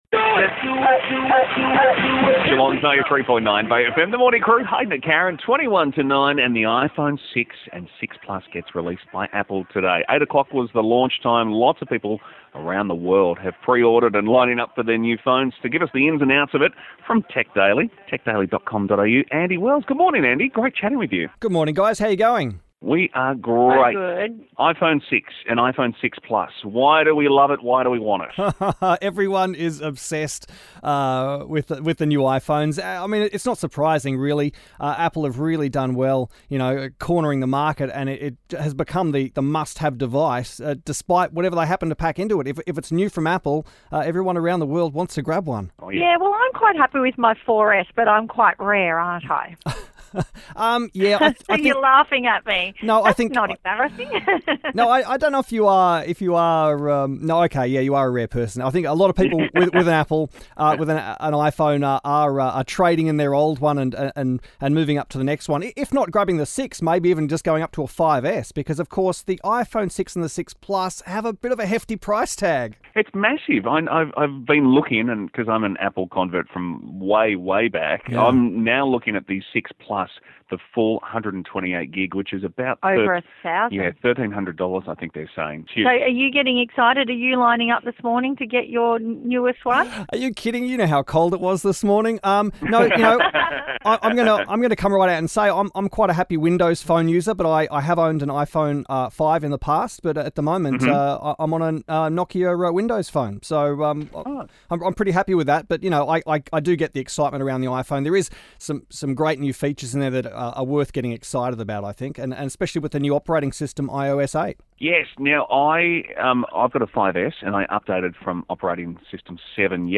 Talking iPhone 6 with Bay FM Geelong
Tech-Daily-on-Bay-FM_iPhone-Launch.mp3